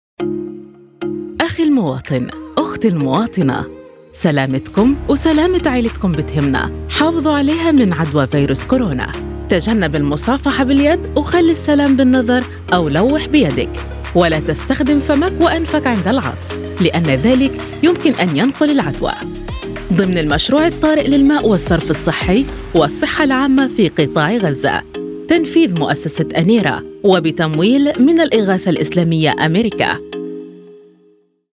PSA on safely interacting with others
This public service announcement will be broadcast in April-May to audiences across Gaza on two popular local radio stations.